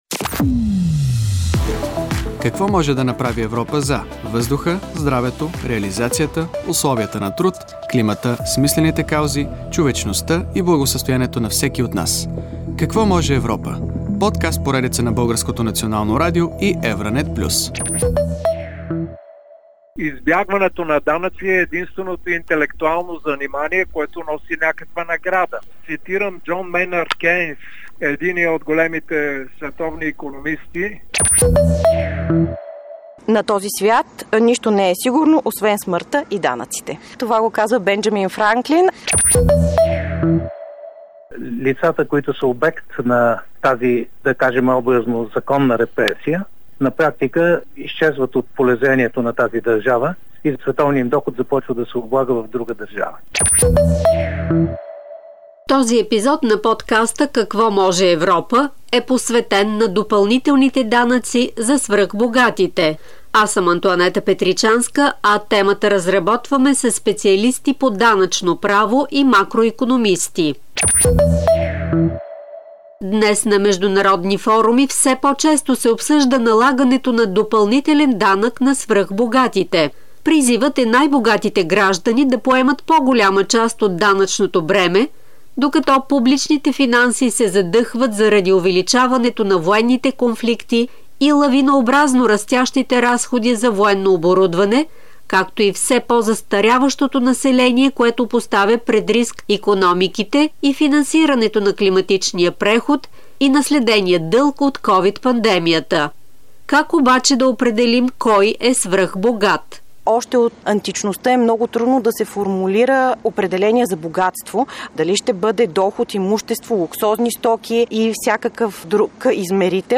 Юлиус Винклер , евродепутат от Демократичния съюз на унгарците в Румъния, част от Европейската народна партия, заместник-председател на Комисията за международна търговия на Европейския парламент